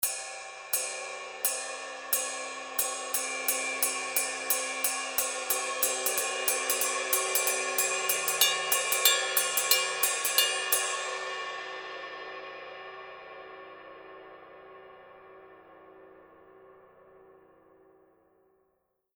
Caractère du son: Brillant, dur, énergique. Spectre assez étroit, mix légèrement complexe. Sensations lourdes. Ping vitreux, puissant qui devient plus sec, plus brut et boisé dans la zone non tournée et plus expressif, croustillant et riche dans la zone tournée. Halo assez brut et terreux qui est très serré et contrôlé. Cloche lourde et perçante. Cymbale ride tranchante et variée avec des zones de jeux distinctes pour une utilisation articulée dans les sets lourds.
signature_20_duo_ride_outer_pattern.mp3